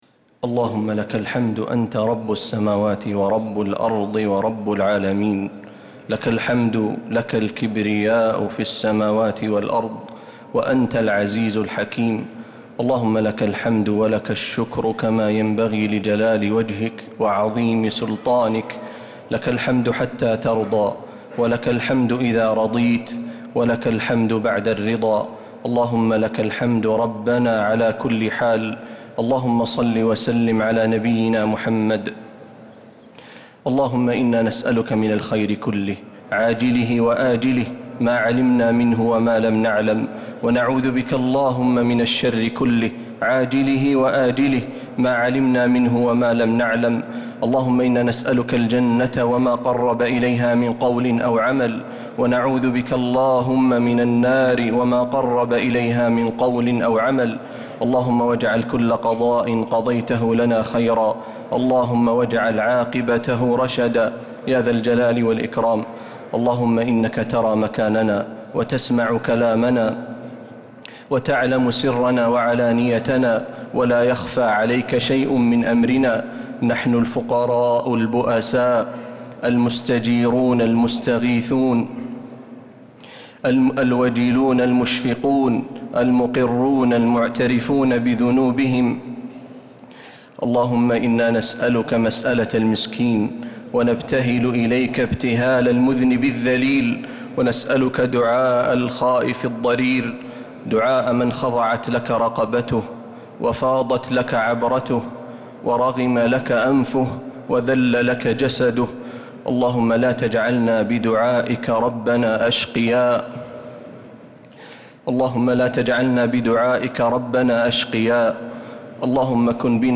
دعاء القنوت ليلة 15 رمضان 1446هـ | Dua for the night of 15 Ramadan 1446H > تراويح الحرم النبوي عام 1446 🕌 > التراويح - تلاوات الحرمين